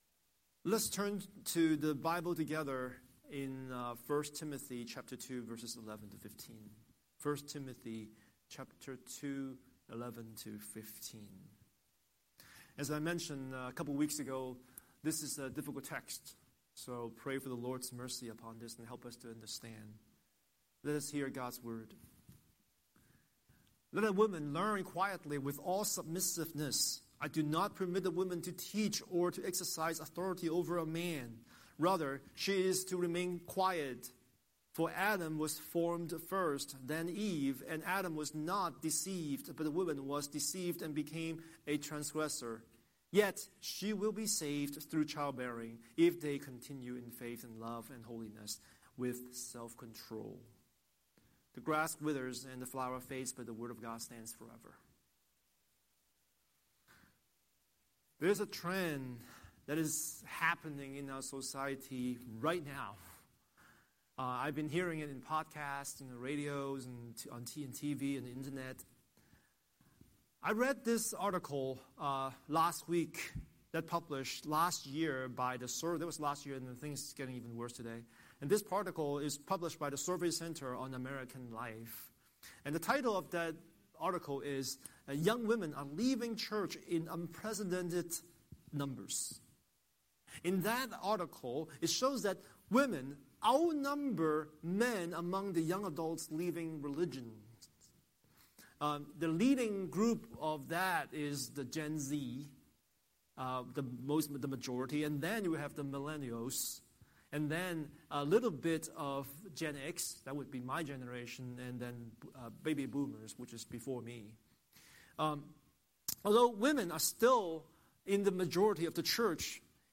Scripture: 1 Timothy 2:11-15 Series: Sunday Sermon